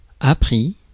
Ääntäminen
Ääntäminen France Tuntematon aksentti: IPA: /a.pʁi/ Haettu sana löytyi näillä lähdekielillä: ranska Käännös Ääninäyte Adjektiivit 1. learnt UK 2. acquired US Suku: m .